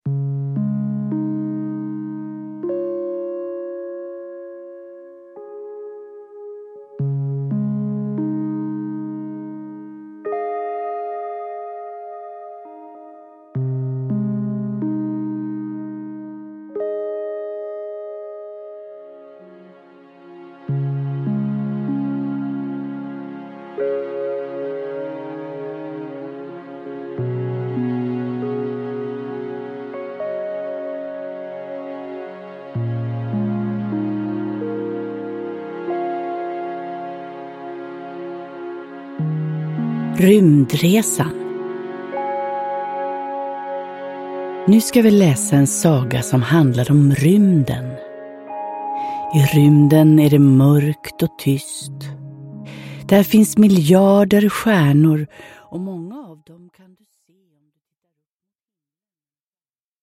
Rymdresan – Ljudbok
Nedladdningsbar ljudbok
Lugna och rogivande sagor som hjälper barnet att somna och som kan bidra till goda sömnvanor också i framtiden.